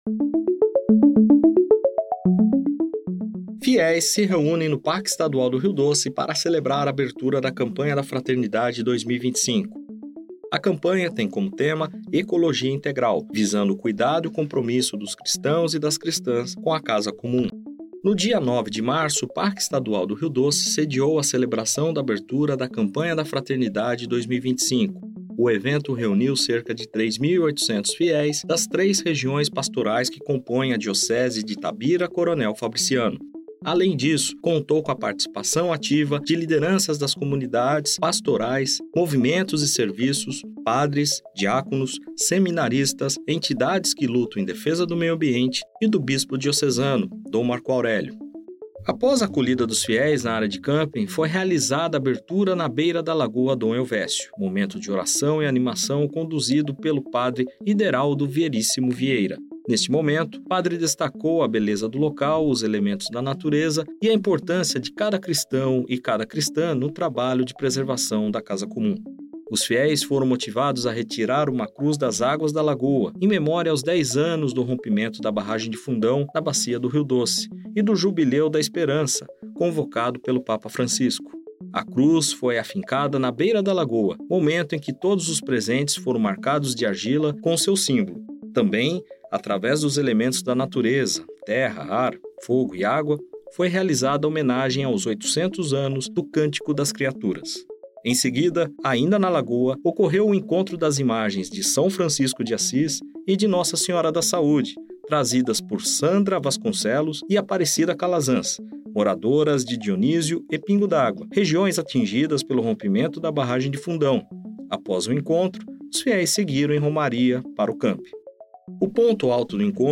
Materia-Campanha-da-Fraternidade.mp3